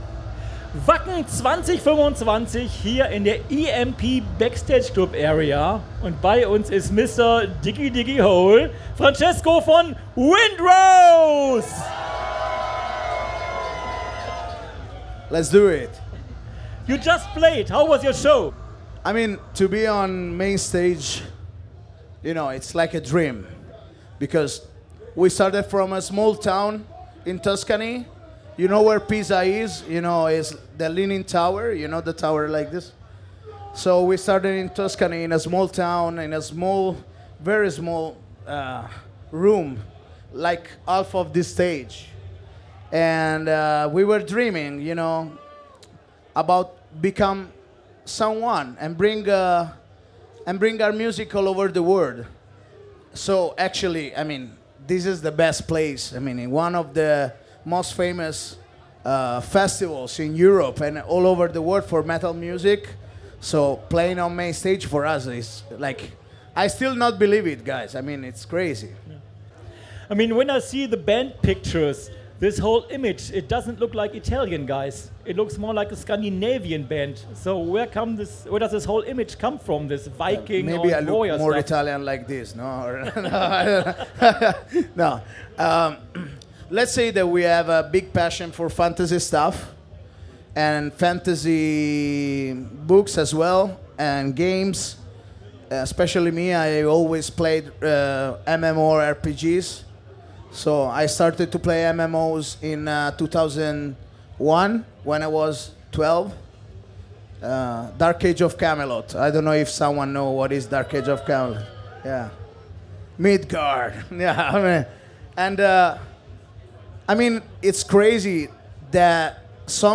Wacken 2025 Special - Wind Rose - Live aus der EMP Backstage Club Area